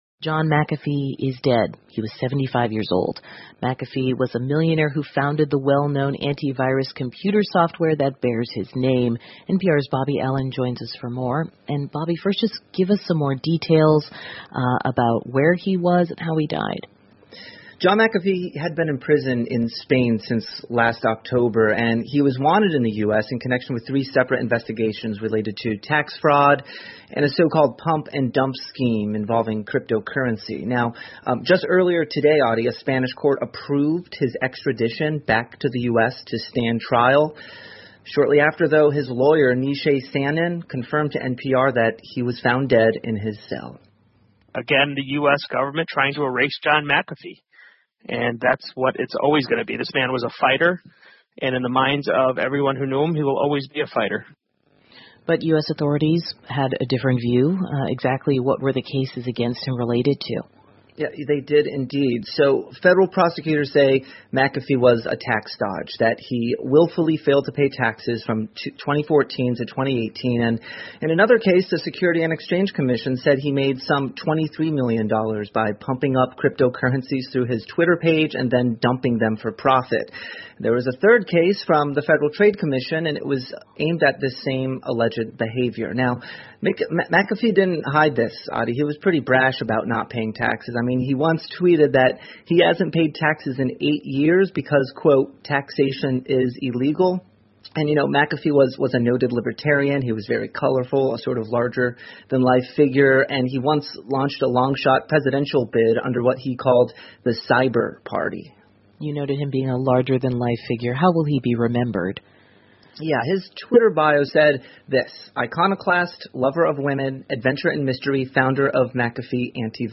美国国家公共电台 NPR "杀毒软件之父"麦卡菲死于西班牙监狱 听力文件下载—在线英语听力室